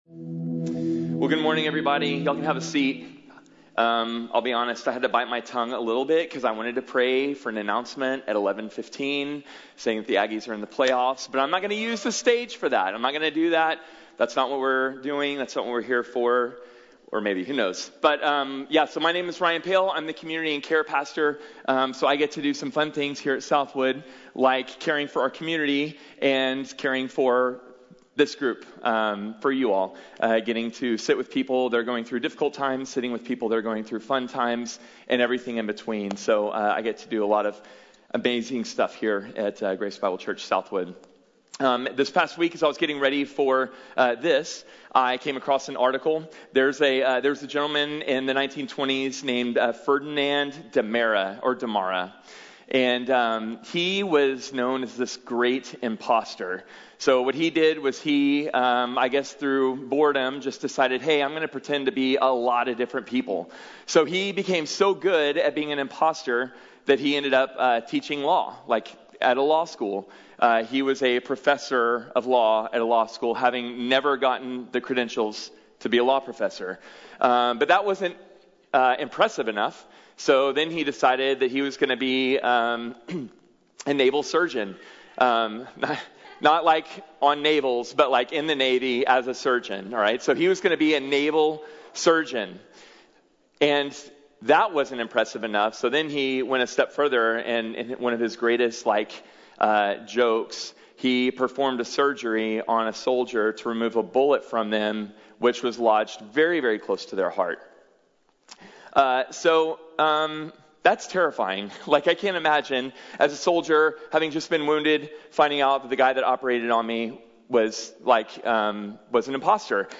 Peace | Sermon | Grace Bible Church